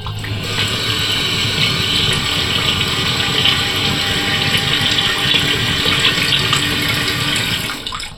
Vaciado completo de una cisterna en una pared en unos urinarios de un bar